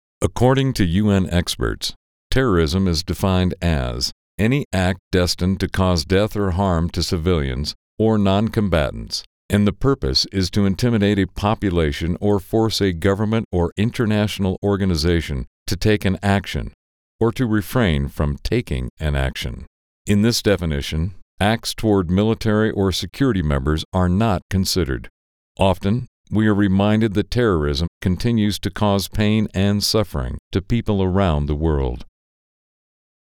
Male
Adult (30-50), Older Sound (50+)
Friendly, smooth, business like, articulate, professional, confident, conversational, calming, authoritative, movie trailer, guy next door, every man, convincing, athletic and a voice that is upbeat and animated when needed to engage the listener.
Confident Corporate